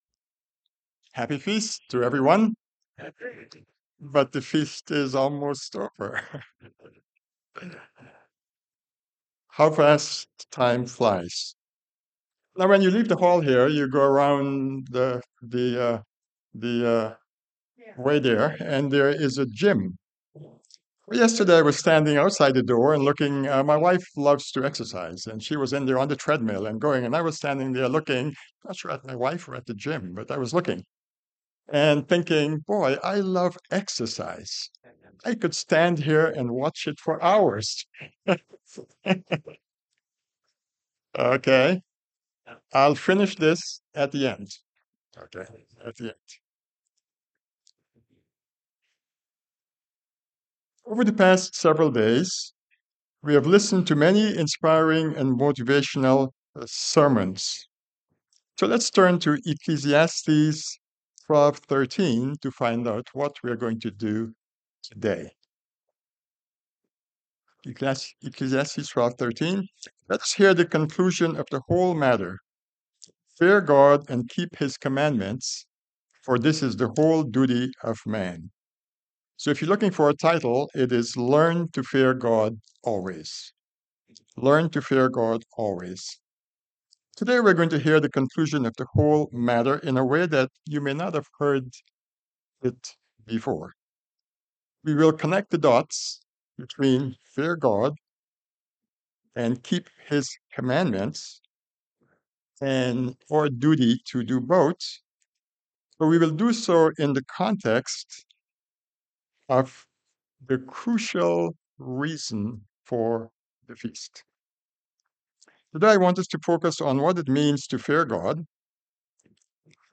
This sermon was given at the Cincinnati, Ohio 2024 Feast site.